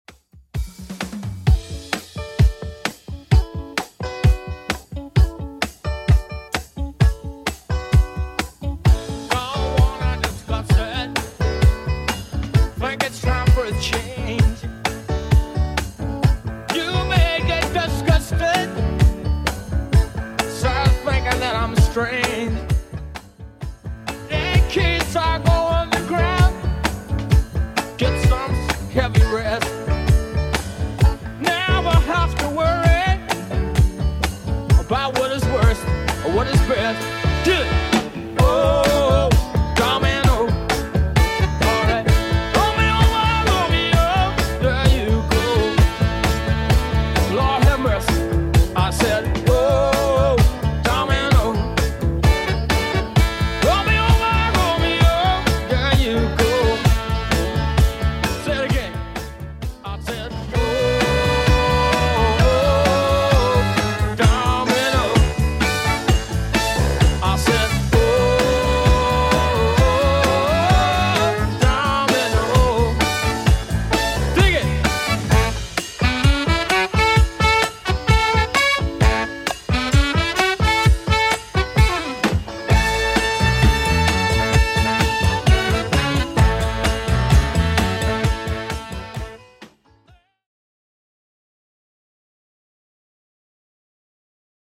Genre: 80's
BPM: 107